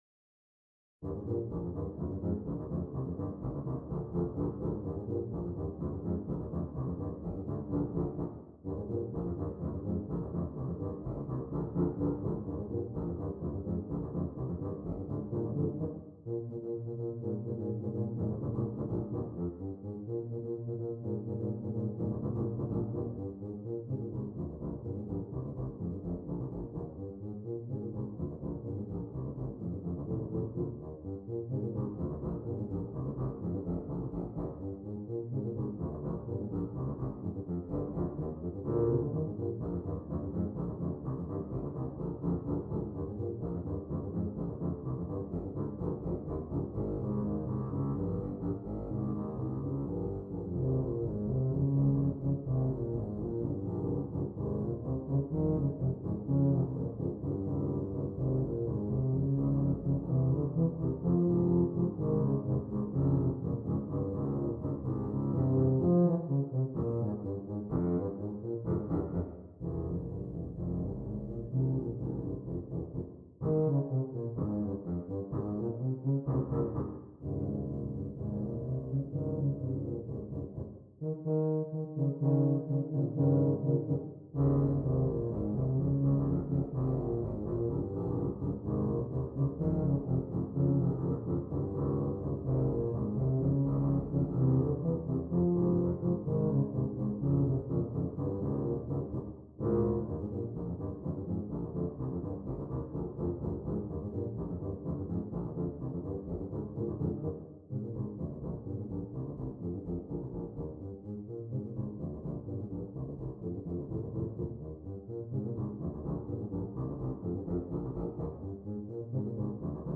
3 Tubas